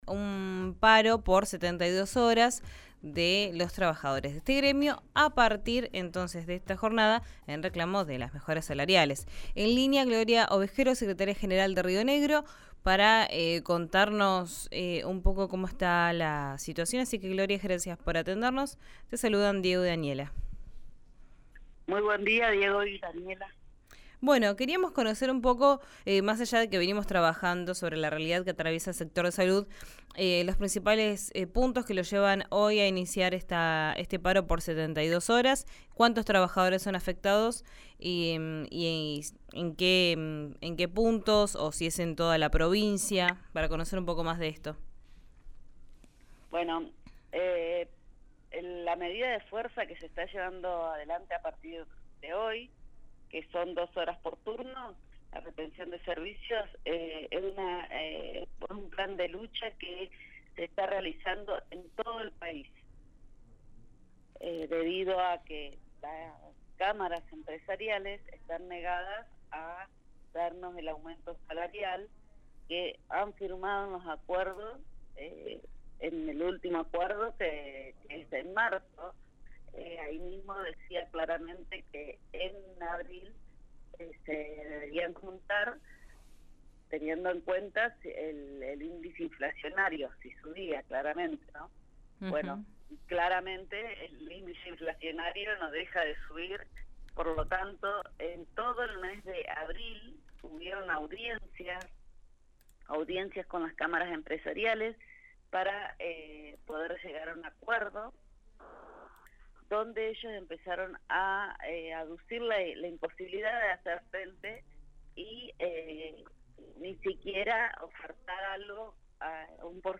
una entrevista con Vos Al Aire de RÍO NEGRO RADIO